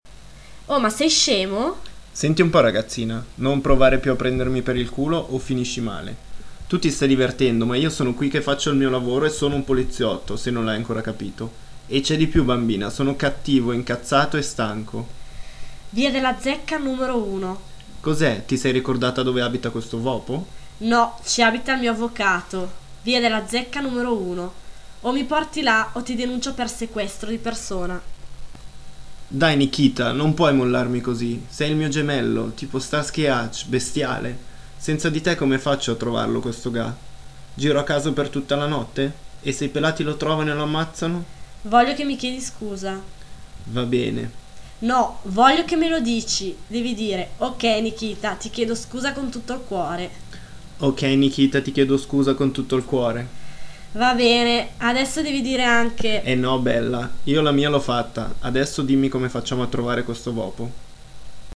dialogo